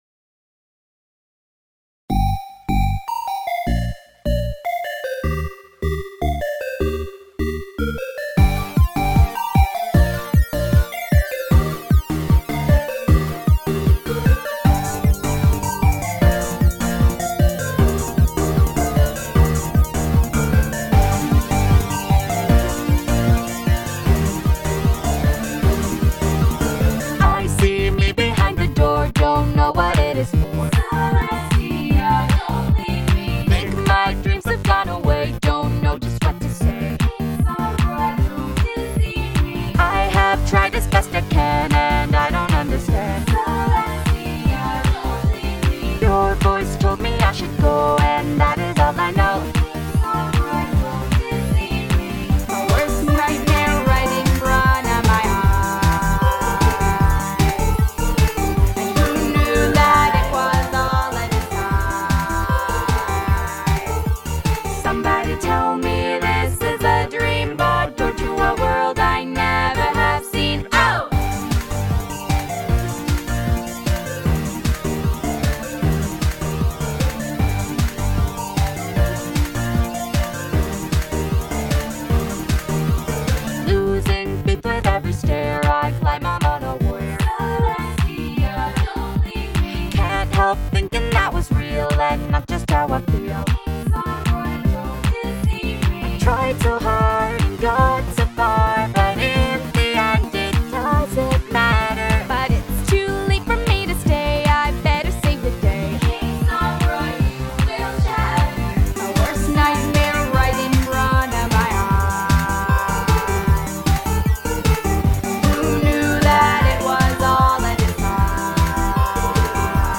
genre:techno